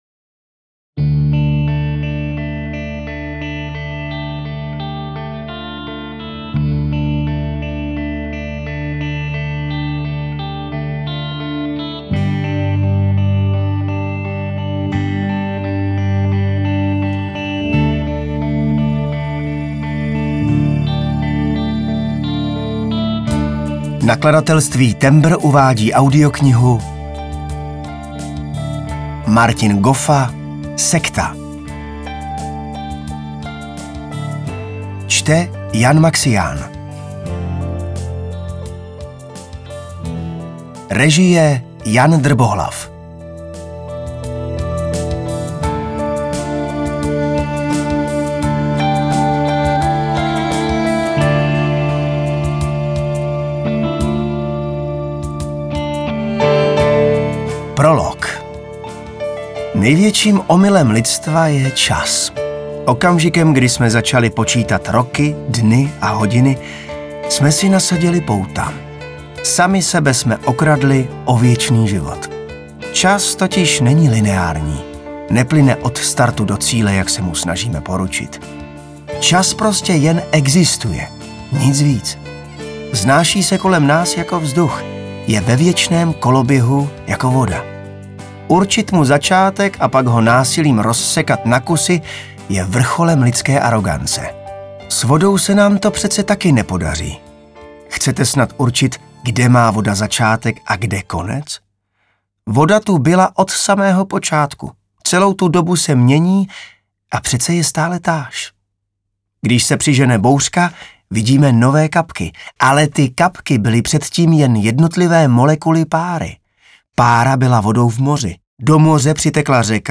audiokniha_sekta_ukazka.mp3